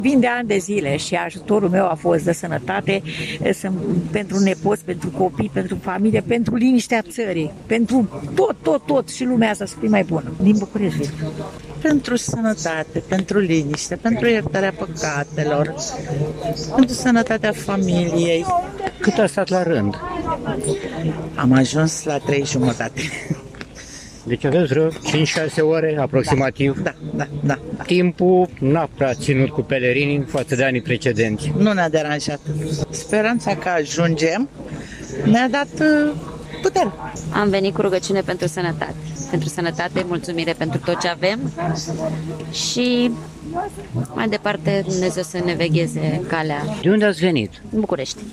Pelerini:
8-oct-rdj-12-Vox-pelerini.mp3